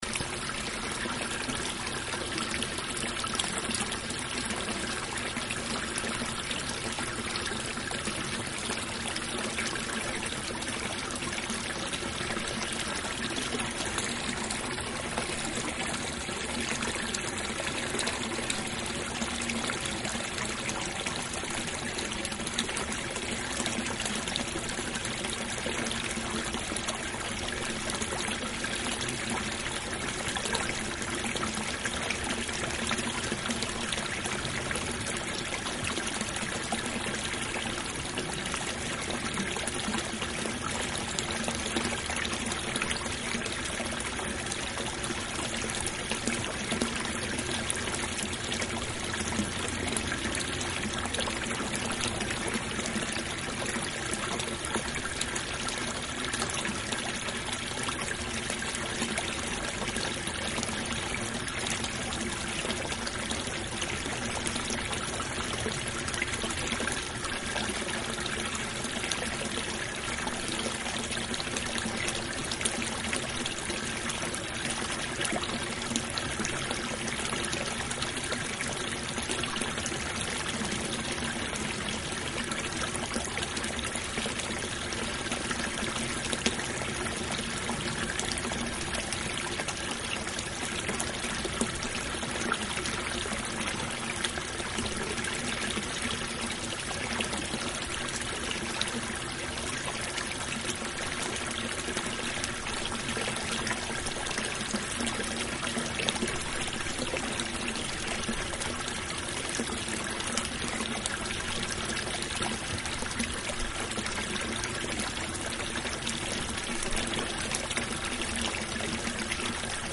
Sounds in the dolphins pilar in the Adarve garden.